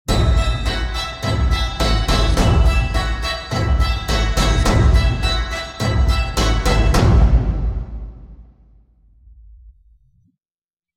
Scary Horror Theme Song Sound Button - Free Download & Play